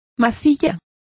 Complete with pronunciation of the translation of filler.